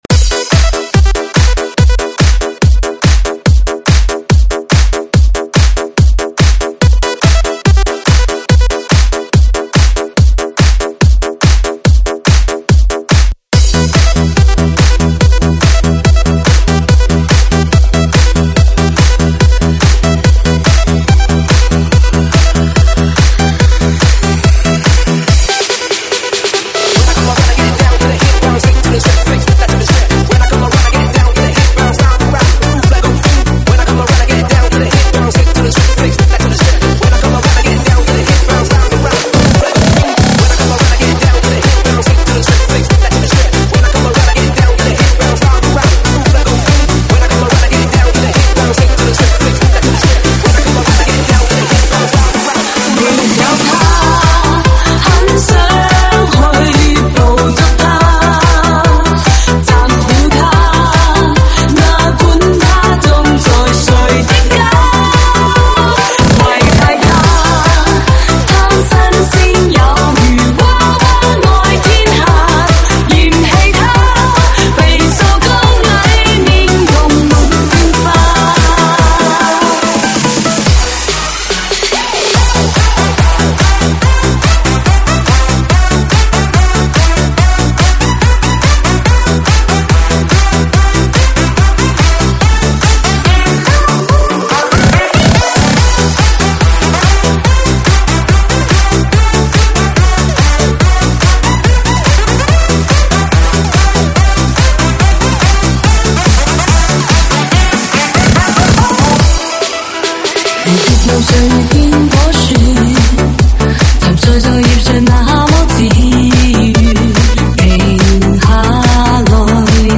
舞曲类别：粤语经典